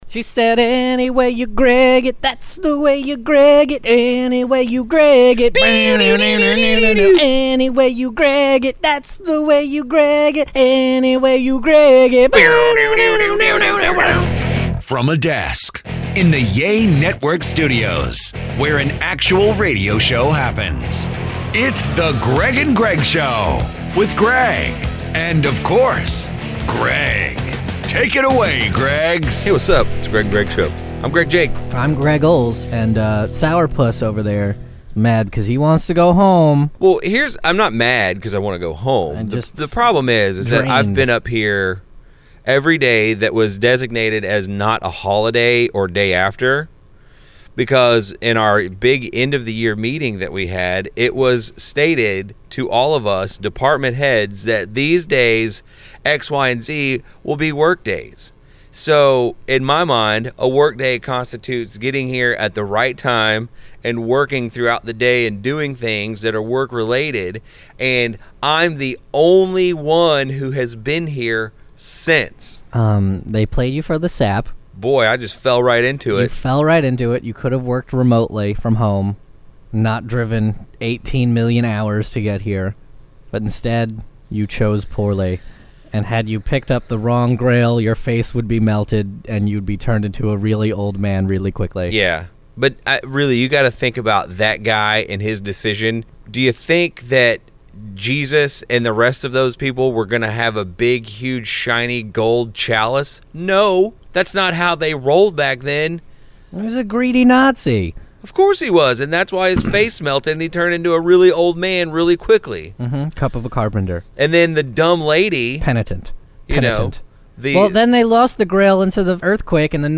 So here is the 1st unprepared and unscripted chat of 2014